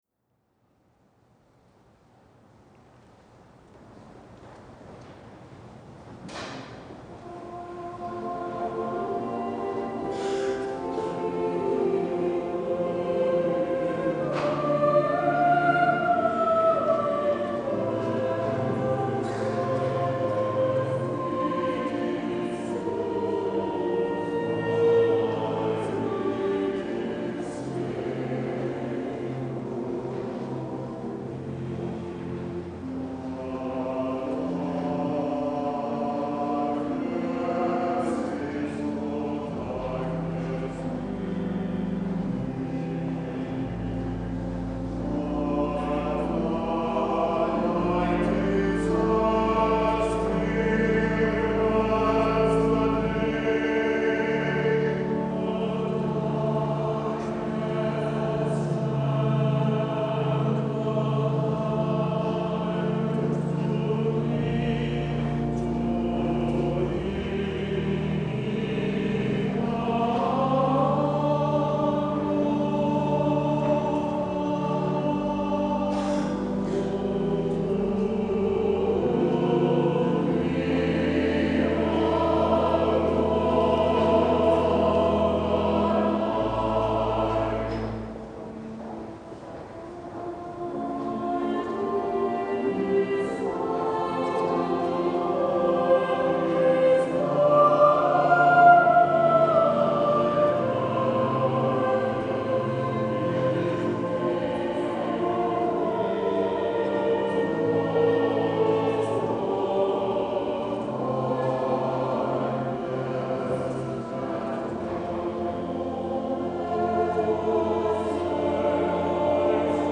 Thou Wilt Keep Him in Perfect Peace – Adult Choir